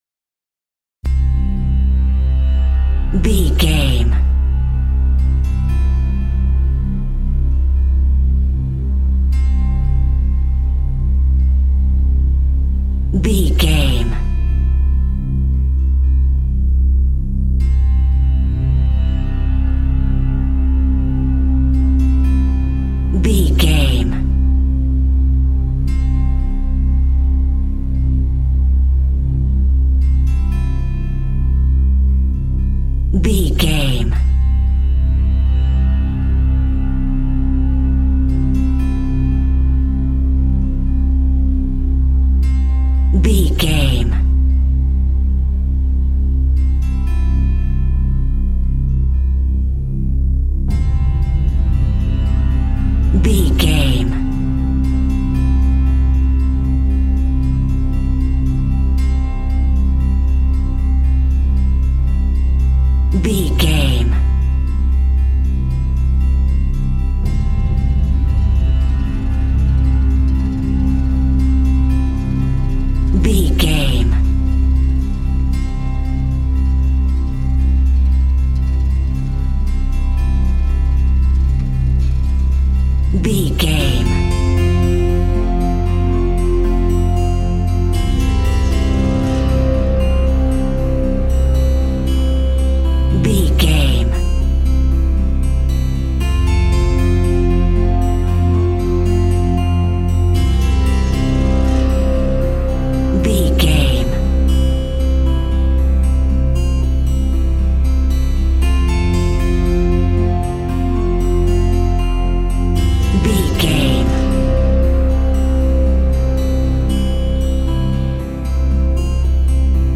Ionian/Major
Slow
synthesiser
drum machine
tranquil